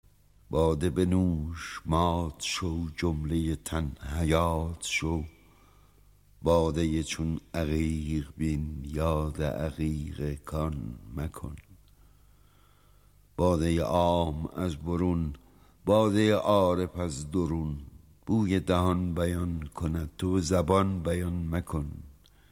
با صدای دلنشین شاعر بزرگ احمد شاملو بشنوید: